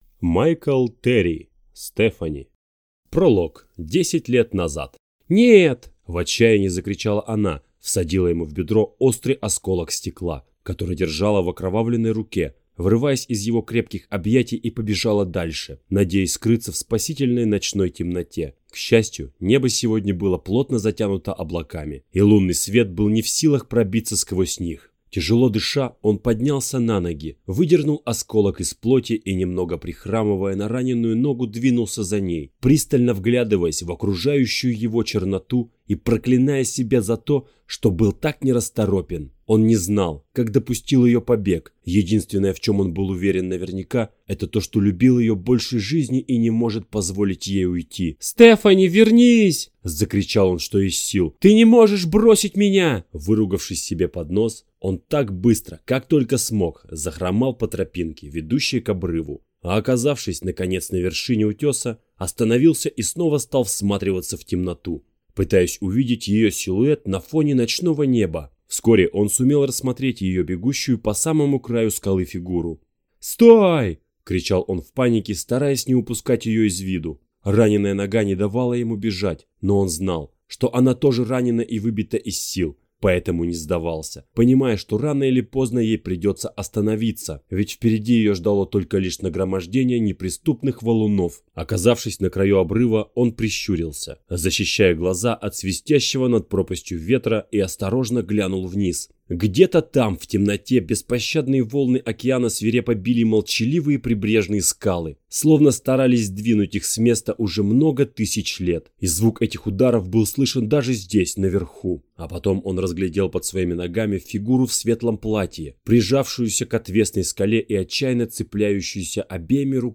Аудиокнига Стефани | Библиотека аудиокниг
Прослушать и бесплатно скачать фрагмент аудиокниги